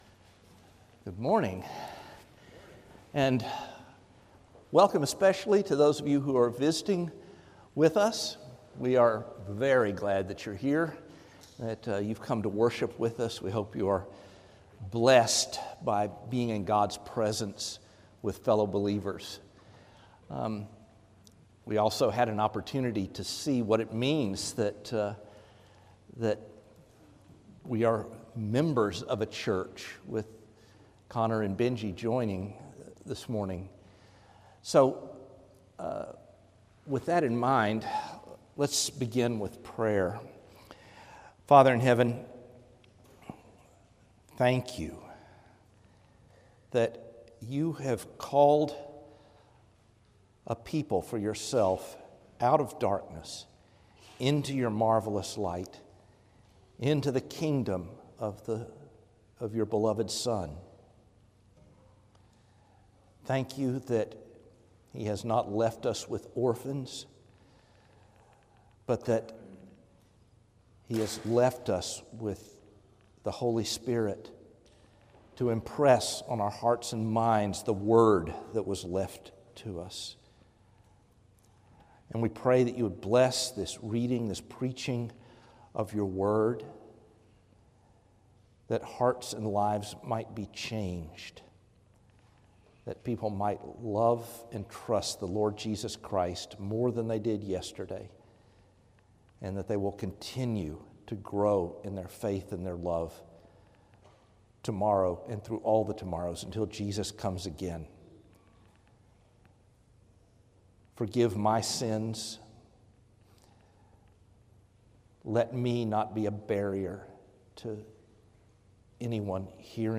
Sermons – Trinity Presbyterian Church